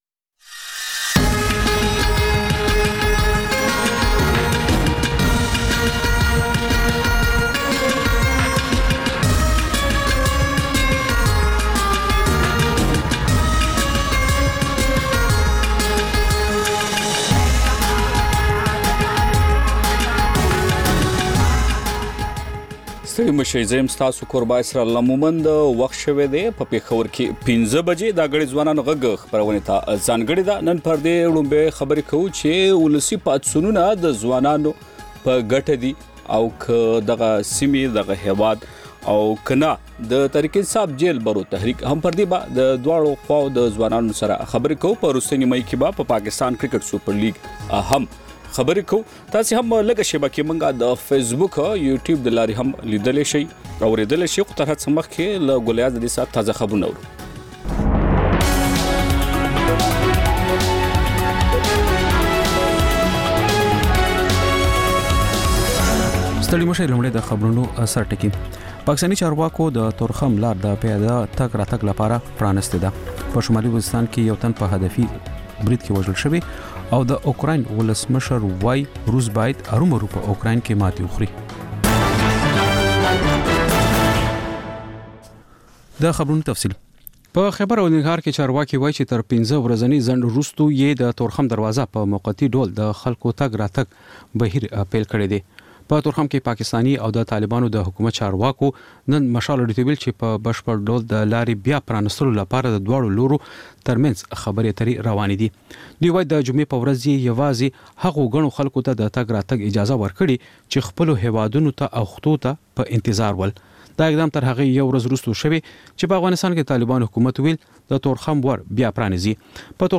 د مشال راډیو ماښامنۍ خپرونه. د خپرونې پیل له خبرونو کېږي، بیا ورپسې رپورټونه خپرېږي.
ځېنې ورځې دا مازیګرنۍ خپرونه مو یوې ژوندۍ اوونیزې خپرونې ته ځانګړې کړې وي چې تر خبرونو سمدستي وروسته خپرېږي.